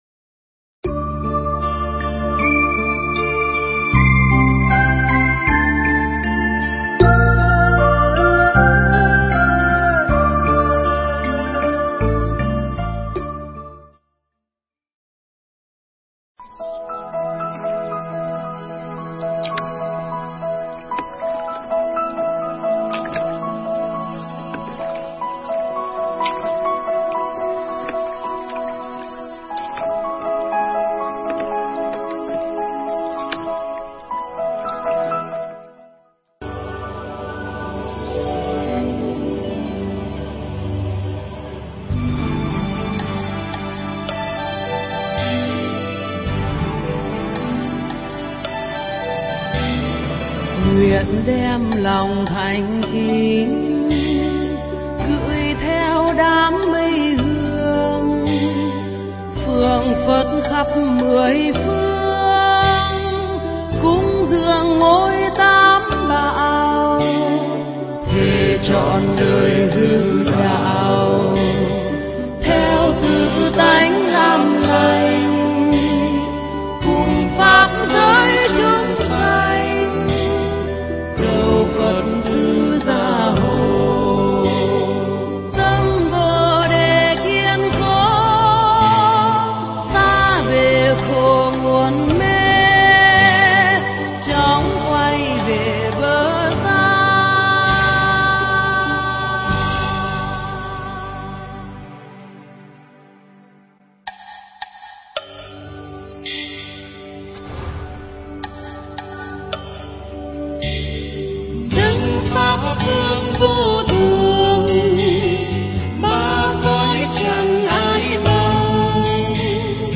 Nghe Mp3 thuyết pháp Giảng Kinh Duy Ma Cật Không Thể Nghĩ Bàn
Mp3 pháp thoại Giảng Kinh Duy Ma Cật Không Thể Nghĩ Bàn